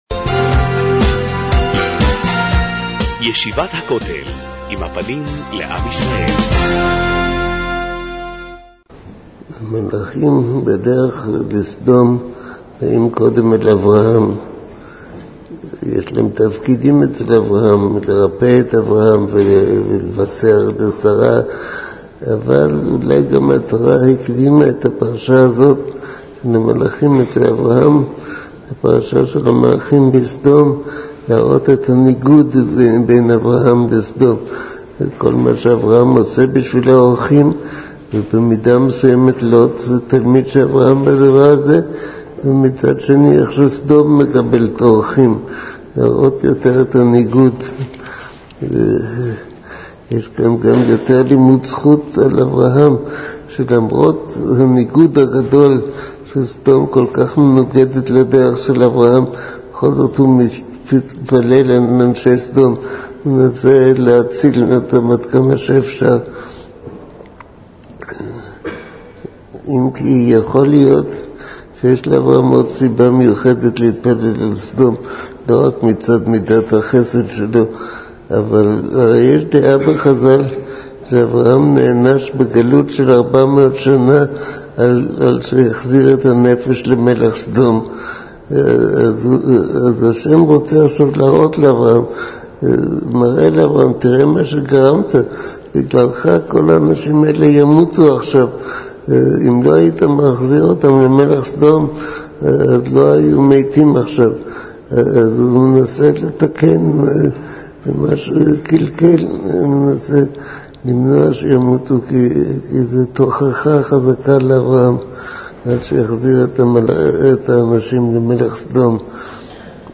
מעביר השיעור: מו"ר הרב אביגדר נבנצל